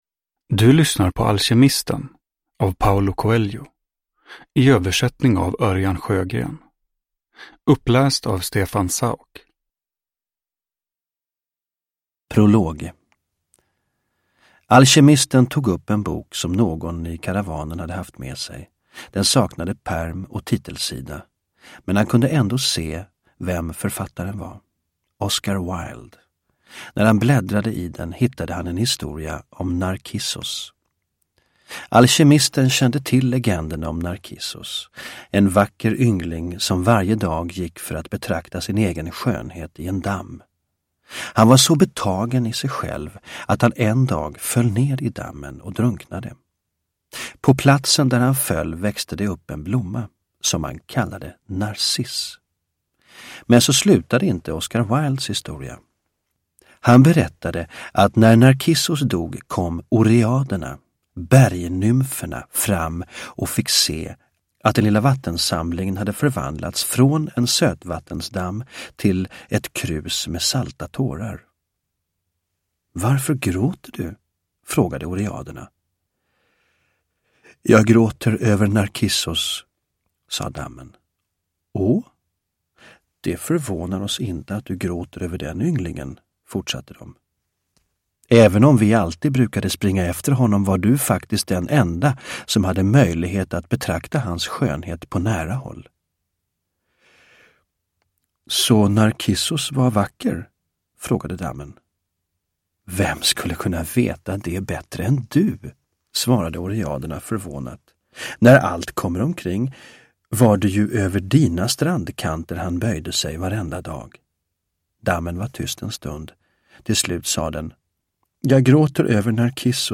Alkemisten – Ljudbok – Laddas ner
Uppläsare: Stefan Sauk